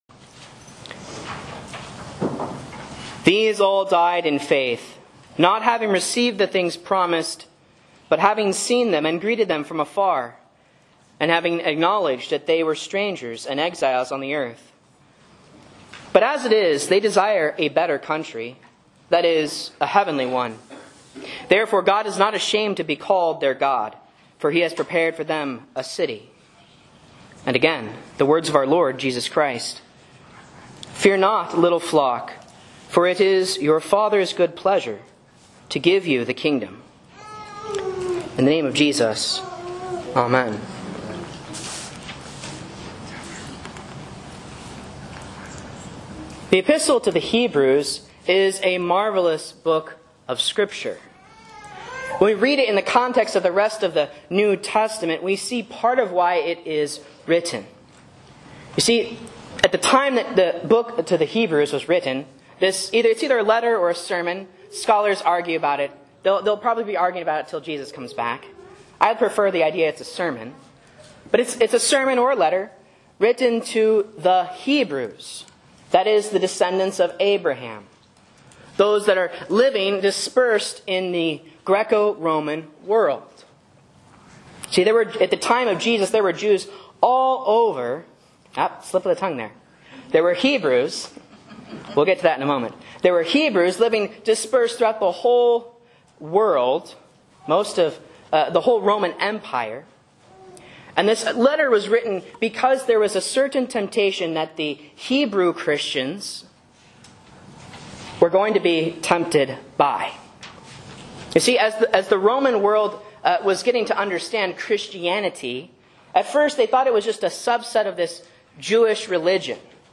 Sermons and Lessons from Faith Lutheran Church, Rogue River, OR
A Sermon on Hebrews 11 & Luke 12:32 for Proper 14 (C)